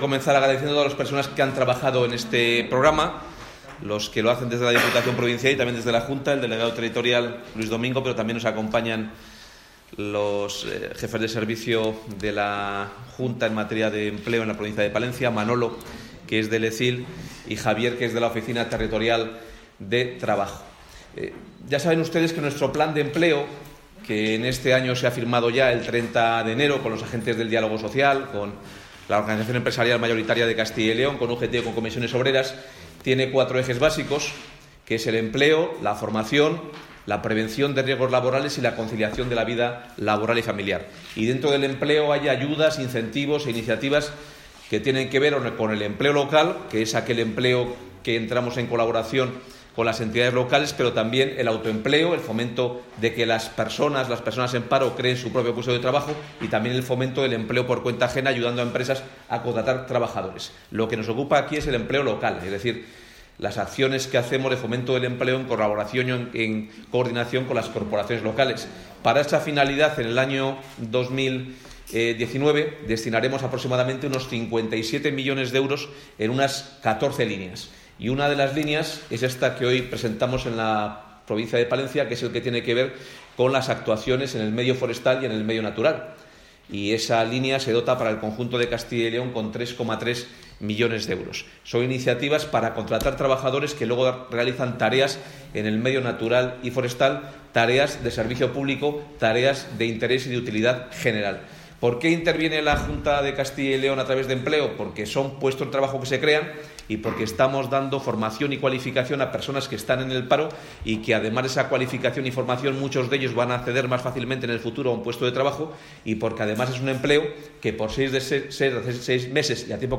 Consejero de Empleo.